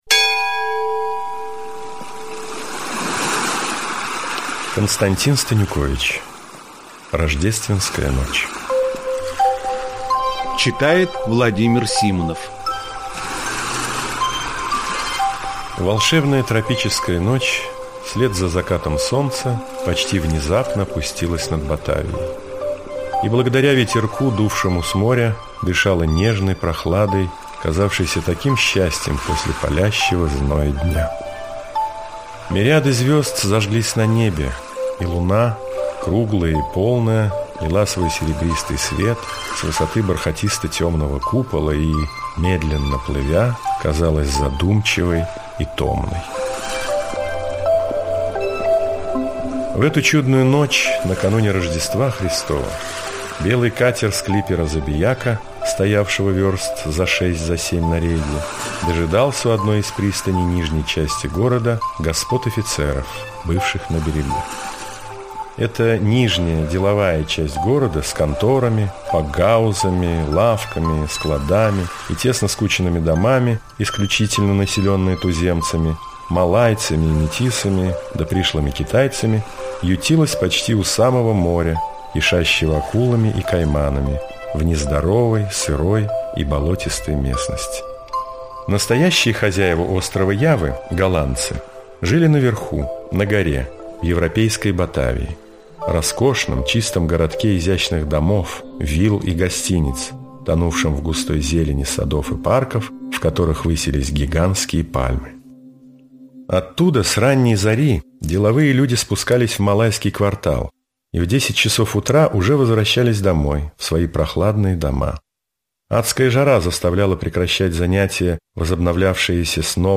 На данной странице вы можете слушать онлайн бесплатно и скачать аудиокнигу "Рождественская ночь" писателя Константин Станюкович. Включайте аудиосказку и прослушивайте её на сайте в хорошем качестве.